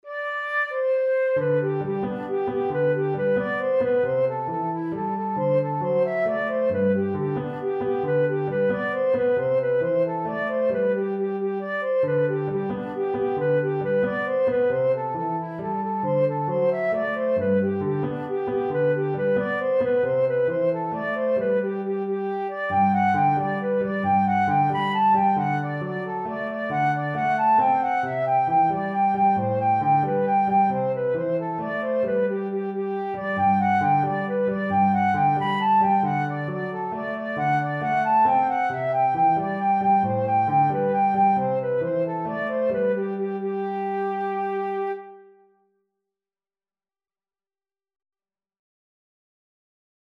Maar de melodie hebben wij voorzien van een nieuw eenvoudig arrangementje, zoals te horen op het apparaatje hieronder.